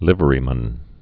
(lĭvə-rē-mən, lĭvrē-)